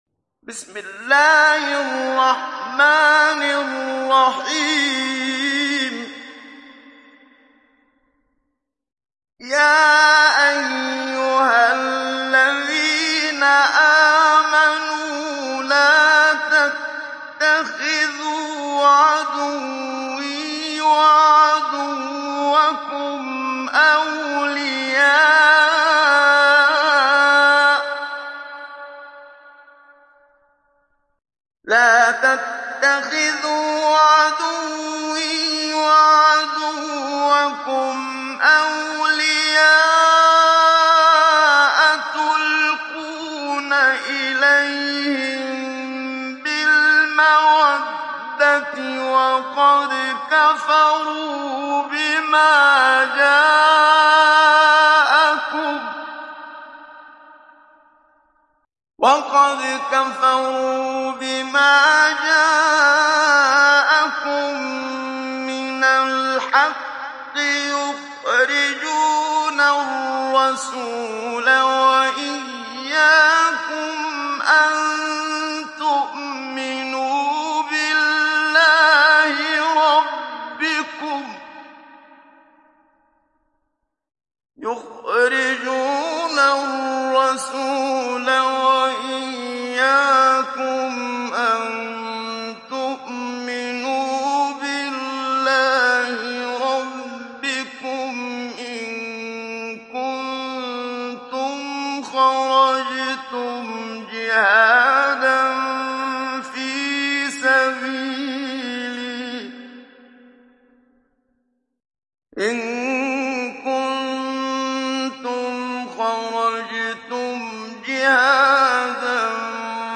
Download Surat Al Mumtahinah Muhammad Siddiq Minshawi Mujawwad